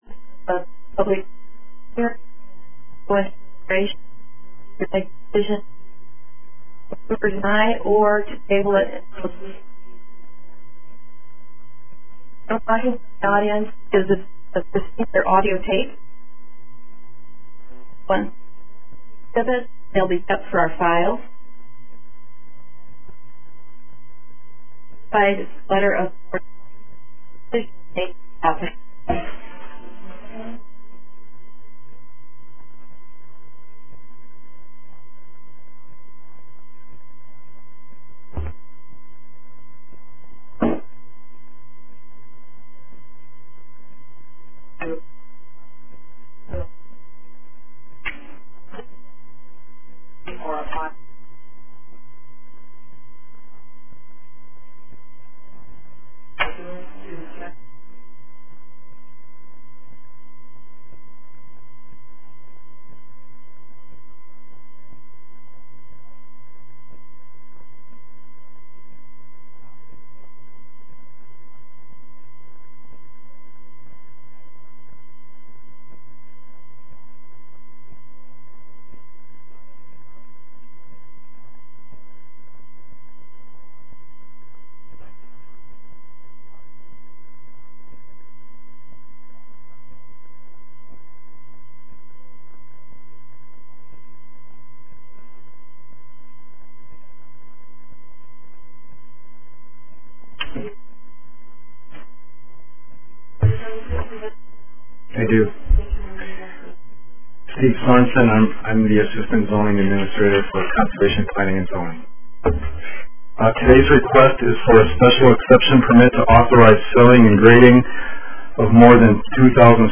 5. Board of Adjustment public hearing beginning at 9:00 a.m.